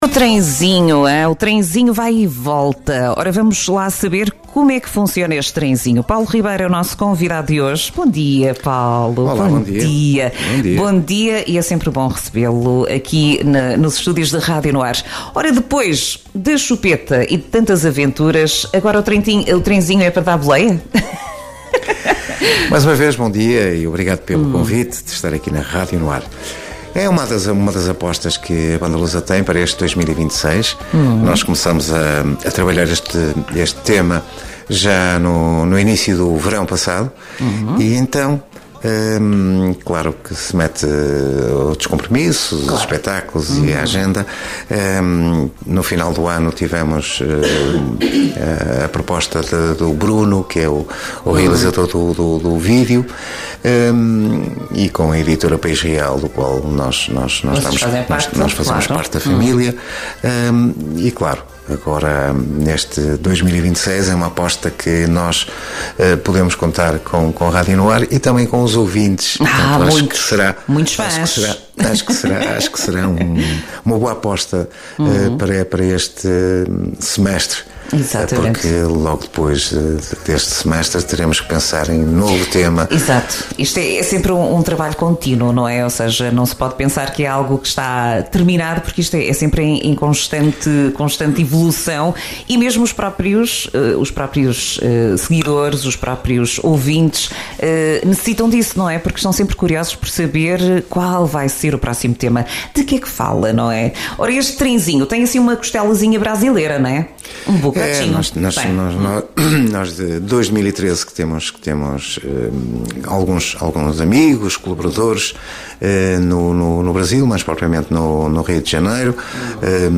ENTREVISTA-BANDALUSA.mp3